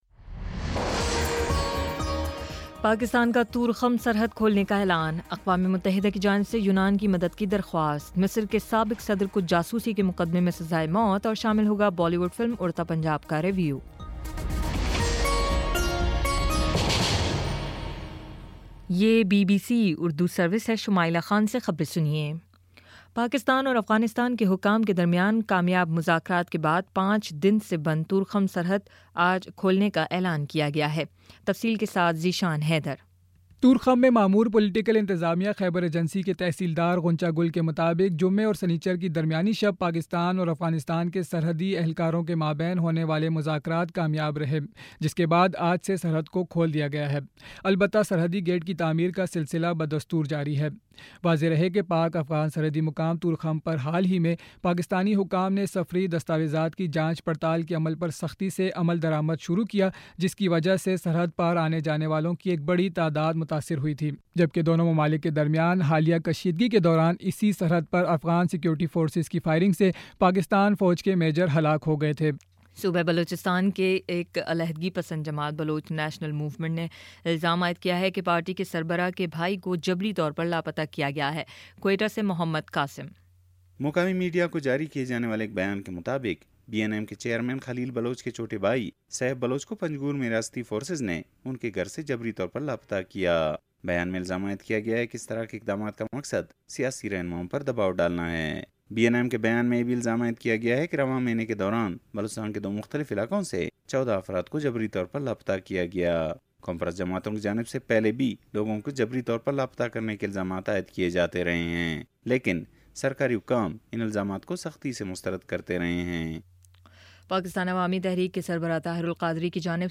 جون 18 : شام چھ بجے کا نیوز بُلیٹن